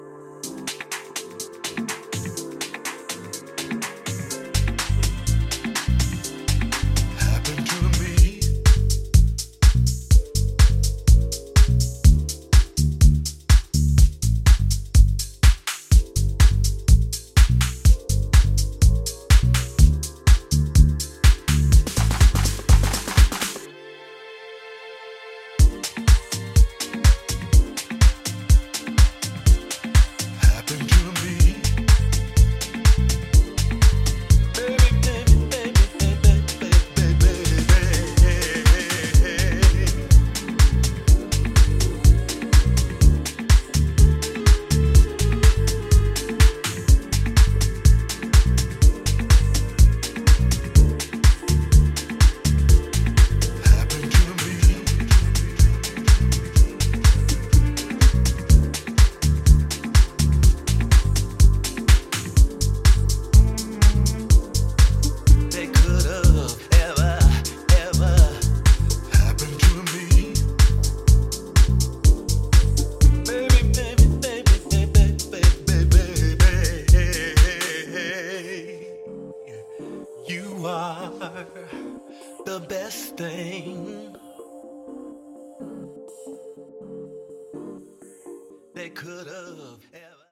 シルキーで研ぎ澄まされたディープ・ハウスを展開しています！